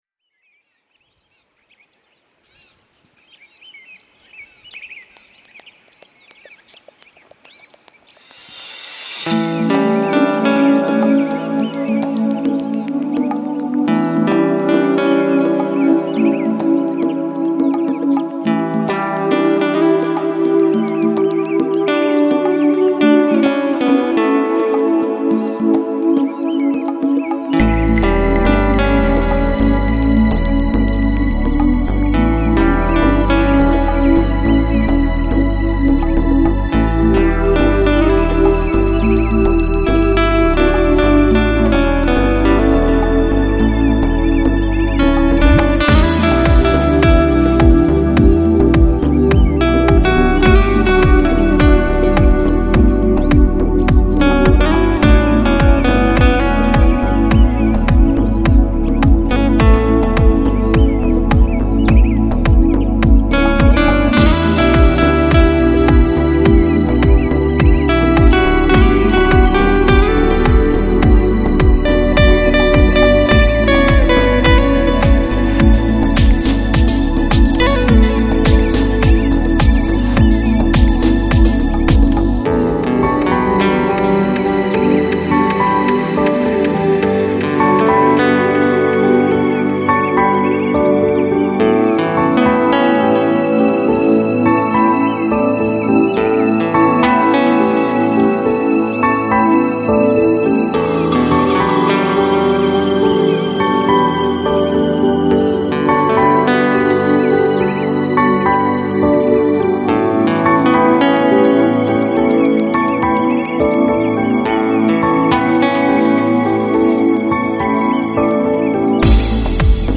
Жанр: Electronic, Lounge, Chill Out, Deep House